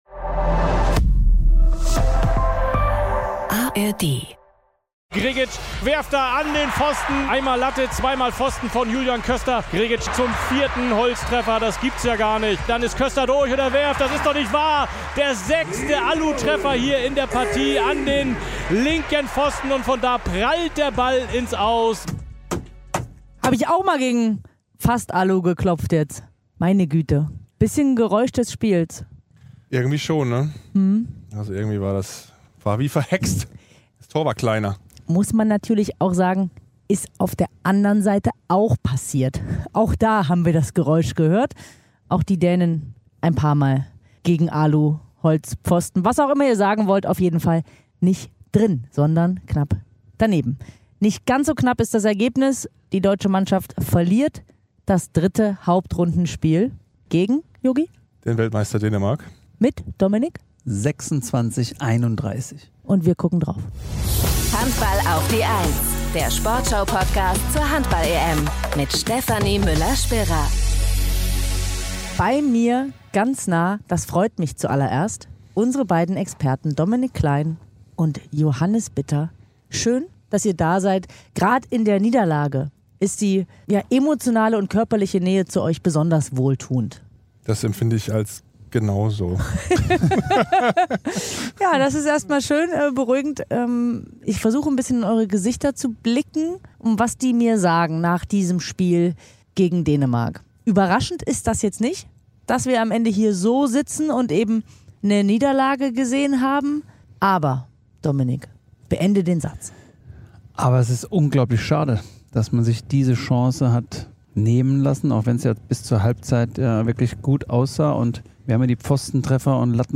Außerdem hört ihr die Stimmen zum Spiel.